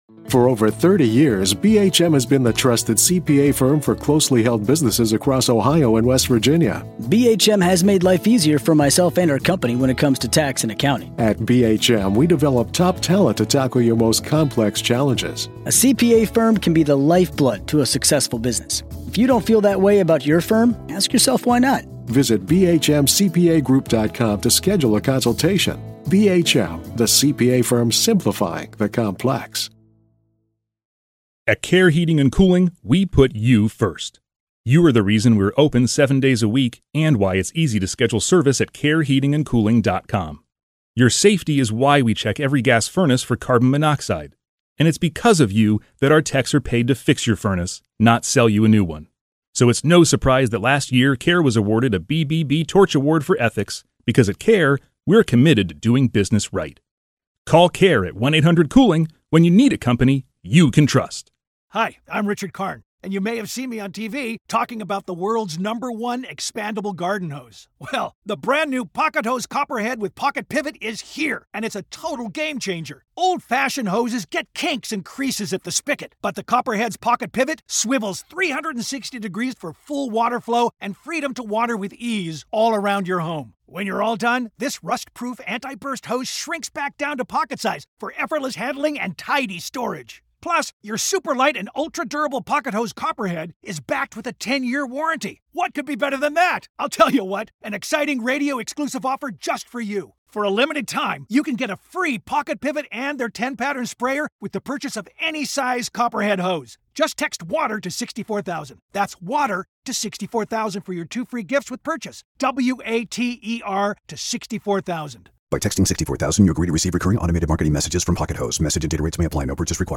True Crime Today | Daily True Crime News & Interviews